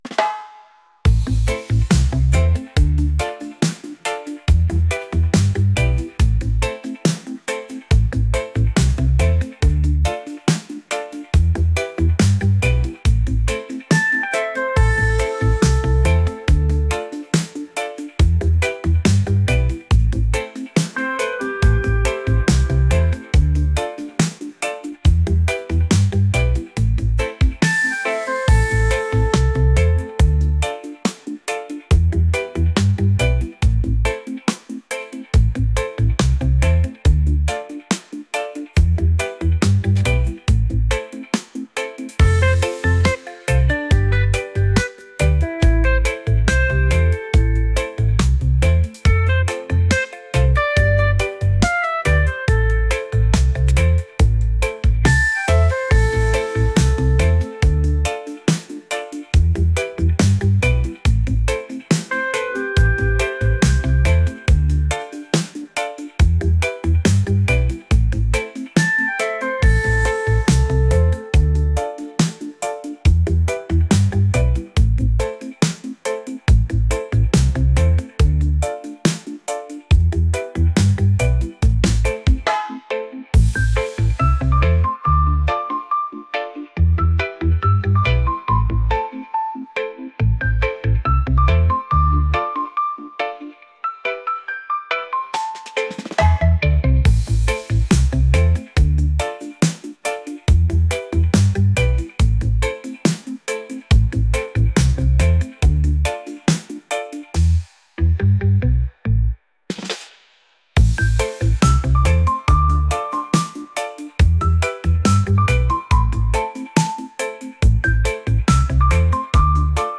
relaxed | reggae